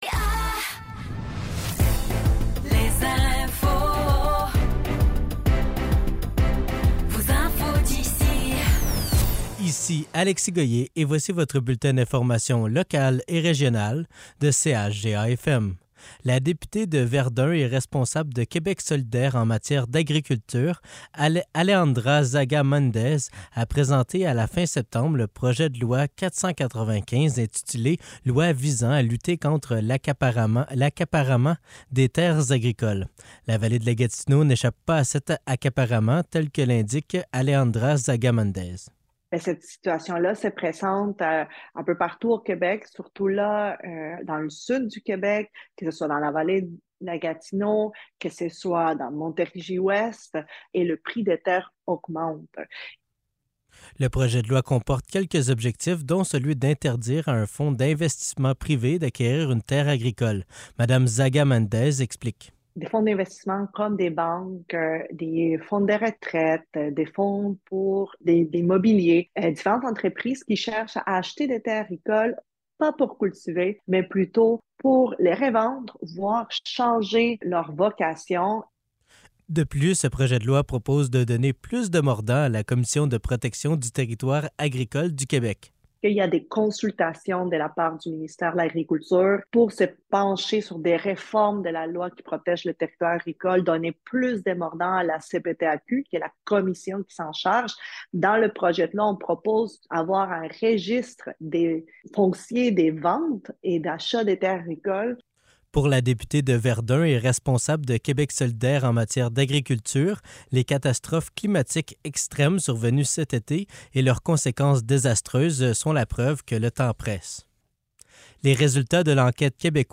Nouvelles locales - 16 octobre 2023 - 15 h